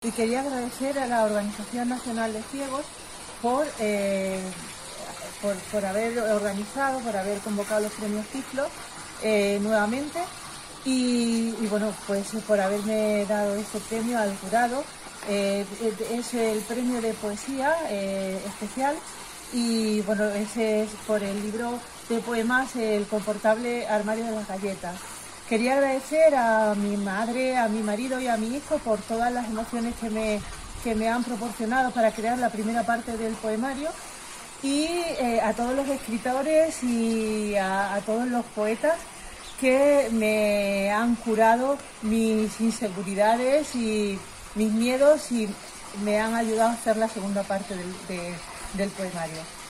Todos los premiados  agradecen el galardón en una gala difundida en Youtube con intervención de todos los jurados y responsables institucionales